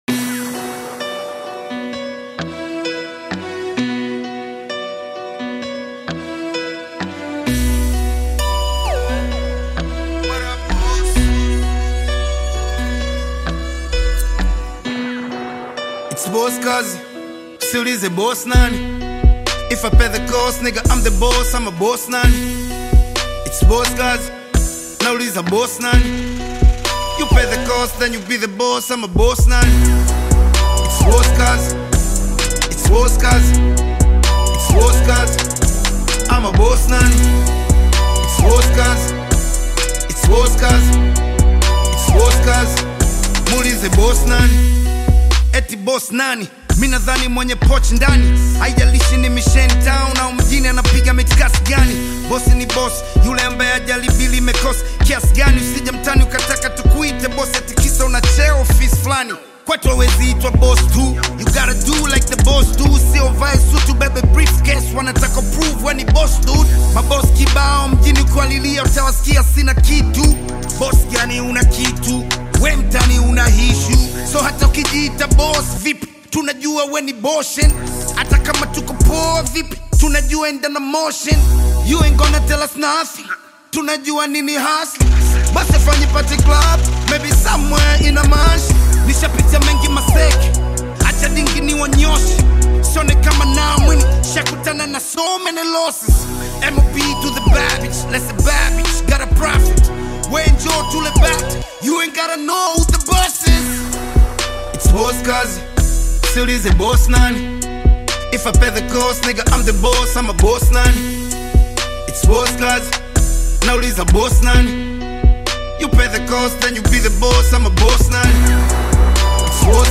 is a prominent Tanzanian hip-hop artist